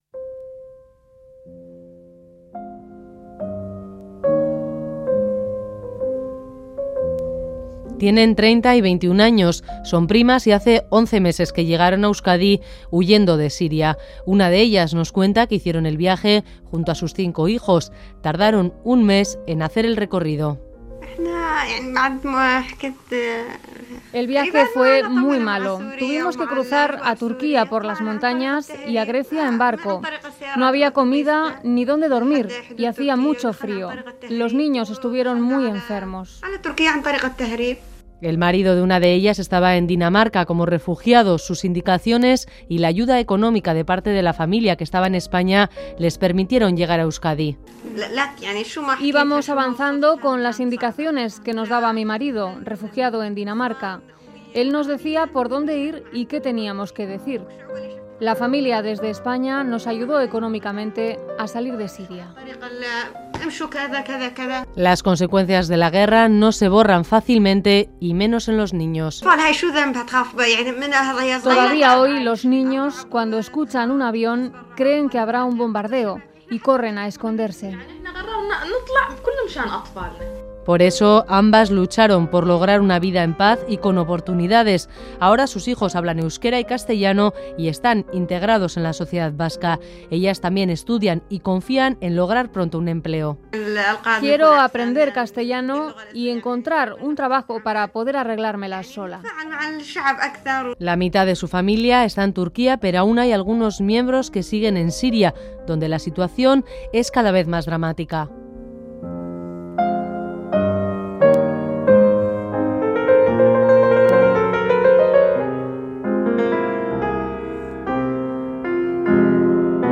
El testimonio de dos mujeres sirias que desde hace 11 meses viven en Euskadi con sus 5 hijos. Tardaron un mes en llegar desde Siria.